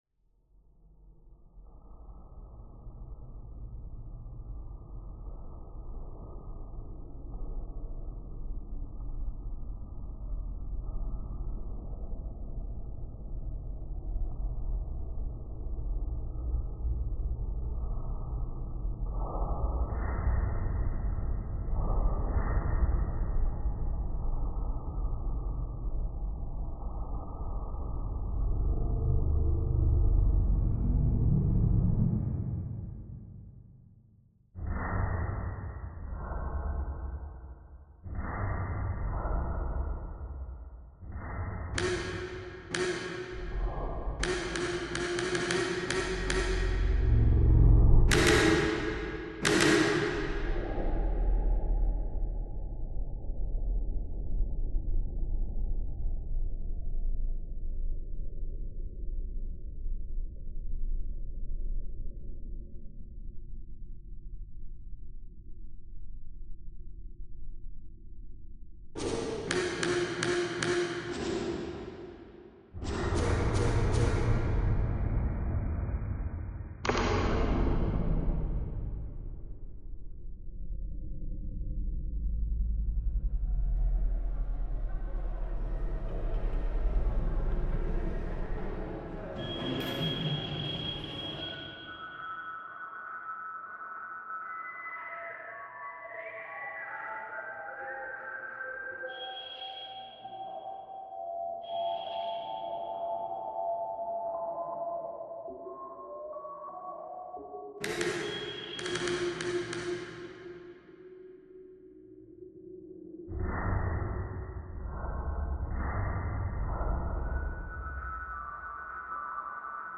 Conversation in Tripoli reimagined